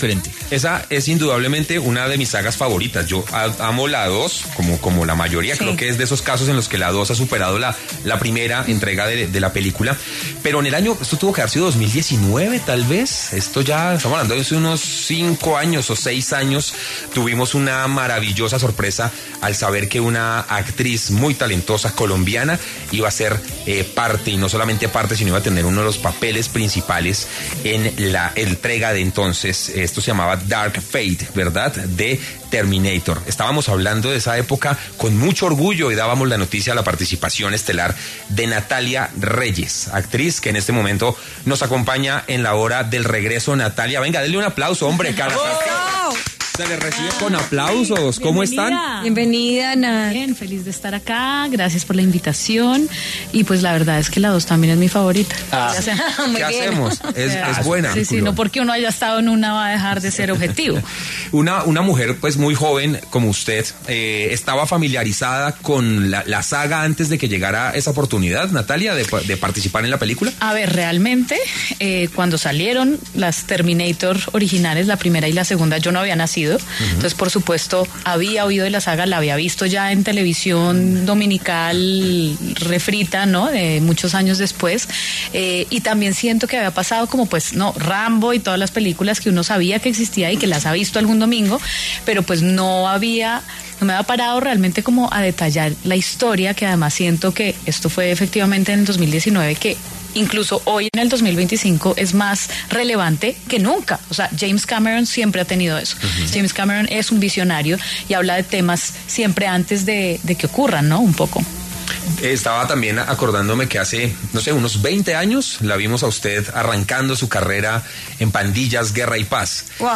Así fue el caso de la actriz Natalia Reyes, quien pasó por los micrófonos de La Hora del Regreso para compartir sus opiniones y experiencias.